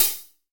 TC3Hat2.wav